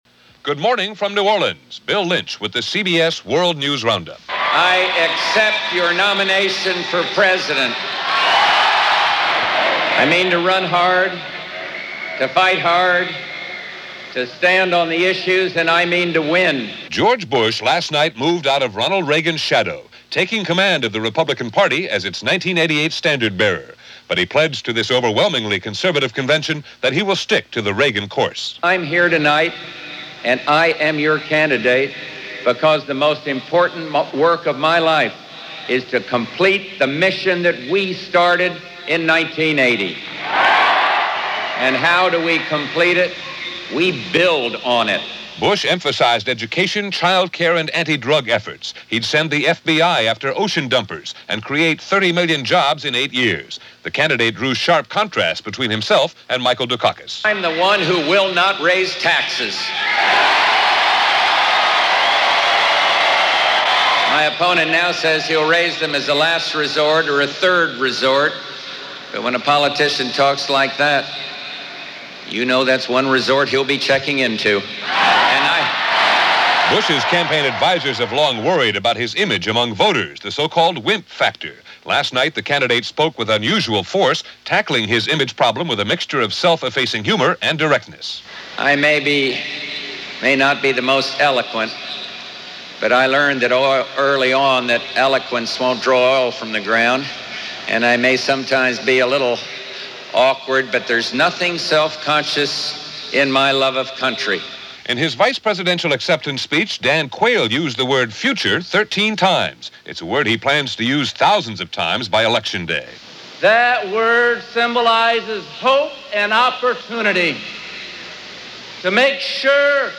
August 19, 1988 – CBS World News Roundup
And that’s a small slice of what happened this particular August 19th in 1988, as reported by The CBS World News Rounup.